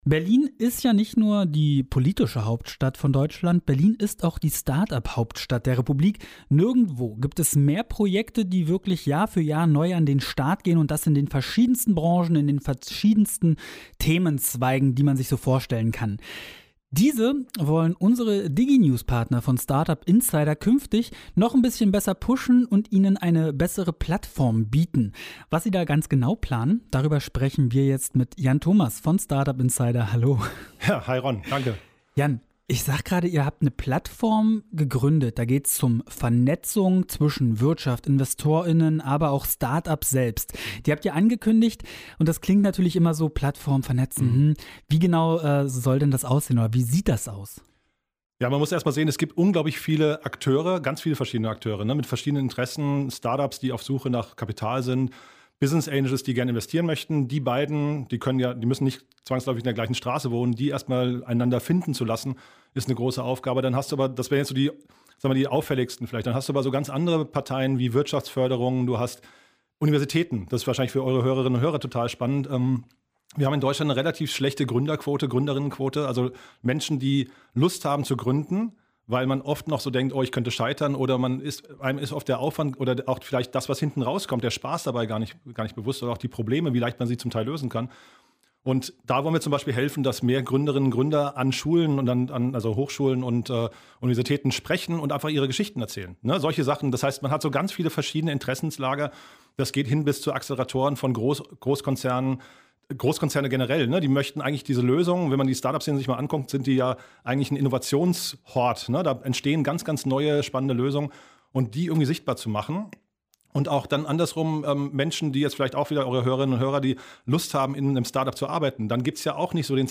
Der Radiobeitrag zum Nachhören